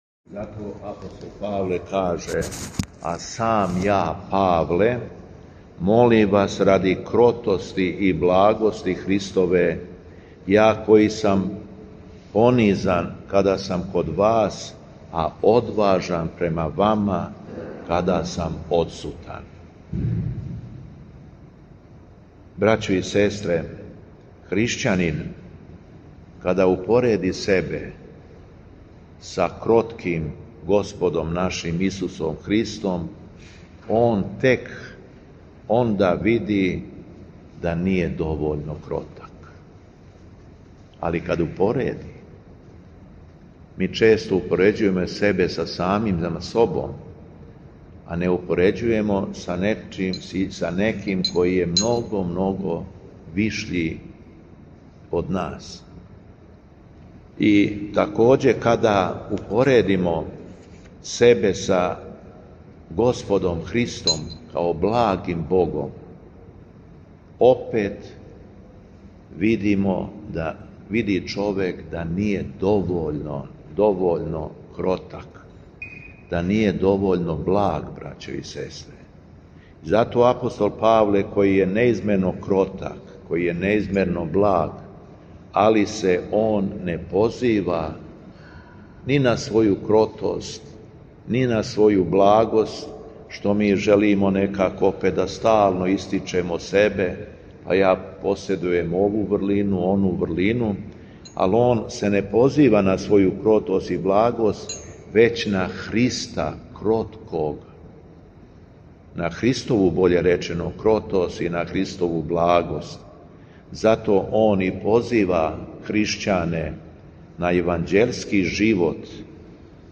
Беседа Његовог Високопреосвештенства Митрополита шумадијског г. Јована
У понедељак сиропусни када наша Света Црква прославља светог и праведног Симеона Богопримца, и светог Јакова архиепископа српскога, Његово Високопреосвештенство митрополит шумадијски Господин Јован, служио је свету архијерејску литургију у храму Светога Саве у крагујевачком насељу Аеродром.